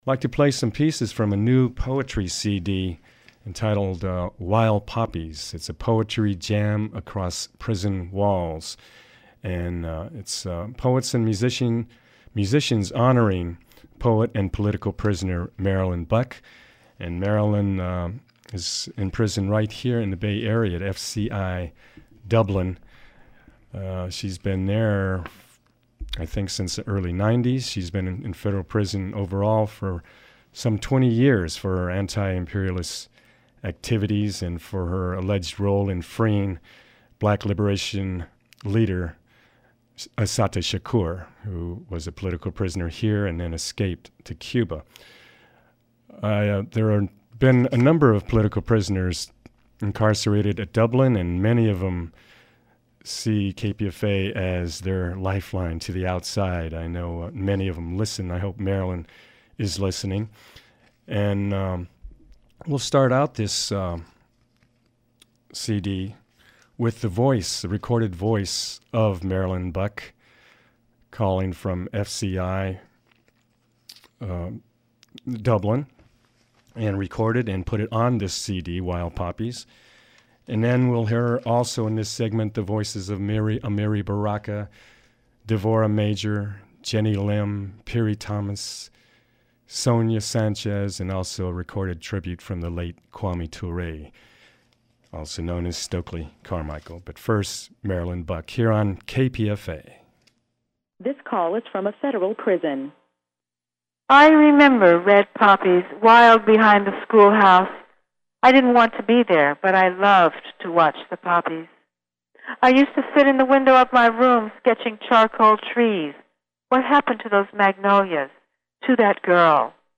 on KPFA Featuring Wild Poppies